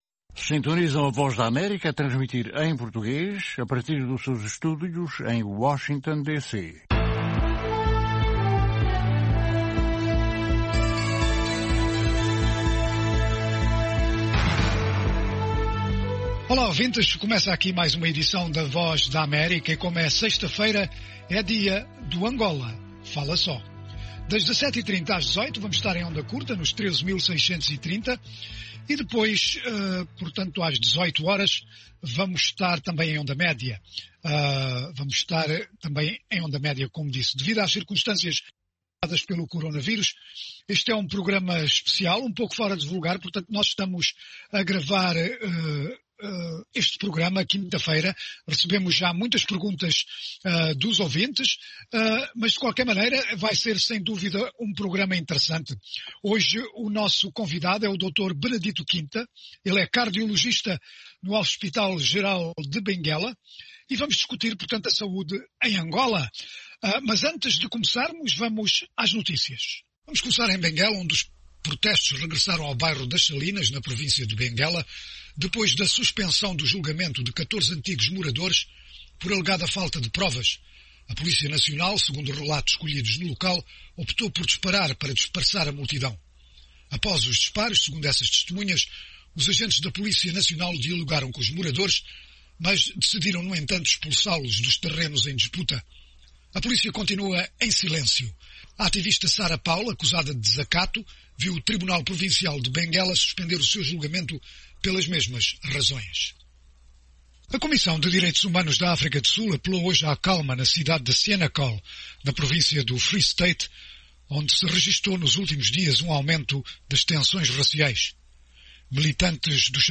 O programa Angola Saúde em Foco é transmitido às sextas-feiras às 17h30 (hora de Angola). Todas as semanas angolanos de Cabinda ao Cunene conversam com todo o país e com um convidado especial sobre os seus anseios e inquetações no campo social e da saúde.